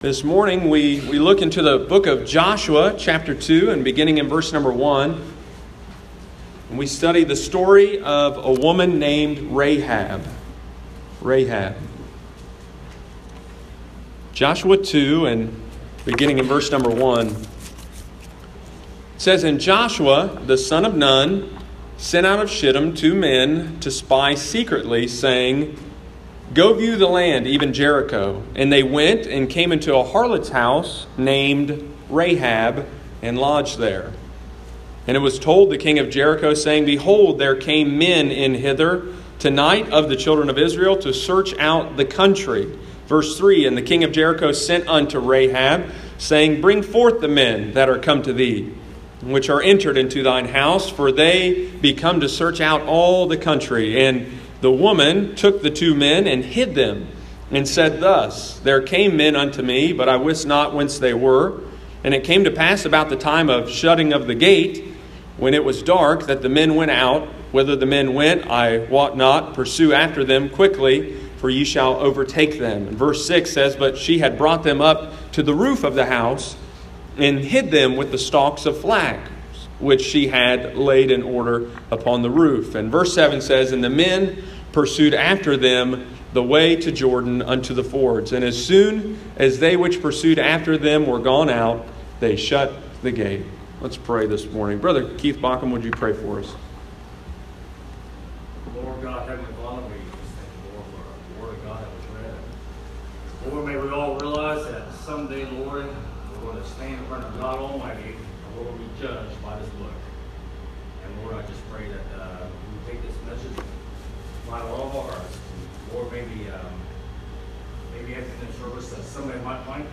This is My Story: Rahab the Harlot Joshua 2:1-7 – Lighthouse Baptist Church, Circleville Ohio